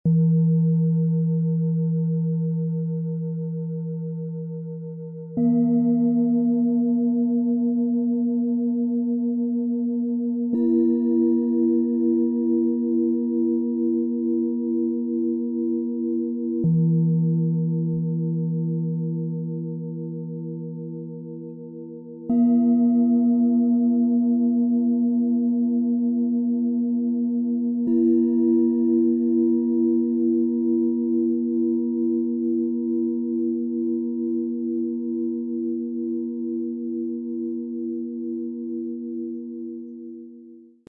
Fröhlicher, ruhiger Aufstieg: zentrierend, aufhellend, leicht - Set aus 3 Klangschalen, Ø 13,5 - 17,4 cm, 1,63 kg
Ein liebevoll abgestimmtes Klangschalen-Set mit klarer Basis, ruhiger Mitte und fröhlichem Klangabschluss.
Tiefster Ton – erdend, stabilisierend, ankommend
Mittlerer Ton – weich, tragend, ausgleichend
Höchster Ton – leicht, fröhlich, öffnend
Die kleinste Schale hebt das Klangbild nach oben. Sie klingt klar, hell und freundlich – ohne sich vom Ganzen zu lösen. Eine heiter-lebendige Klangnote mit innerem Fokus.
Im Sound-Player - Jetzt reinhören können Sie den Original-Ton genau dieser Schalen, des Sets anhören.
MaterialBronze